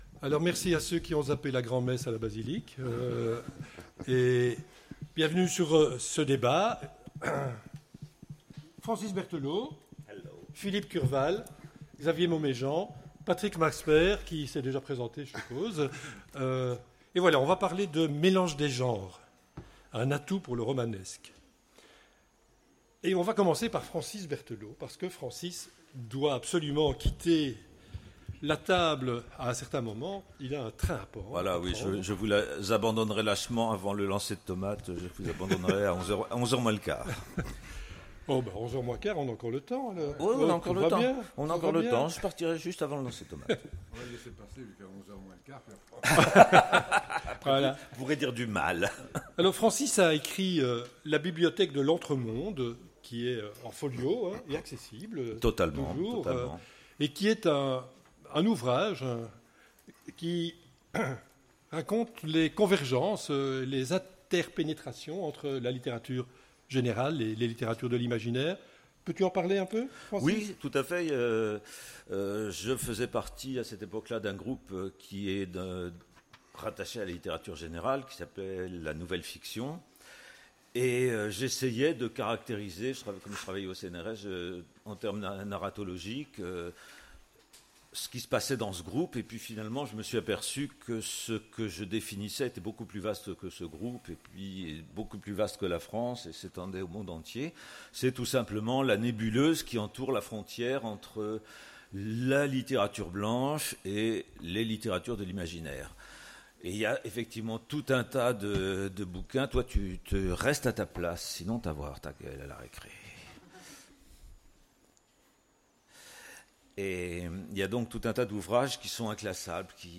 Imaginales 2016 : Conférence Le mélange de genres…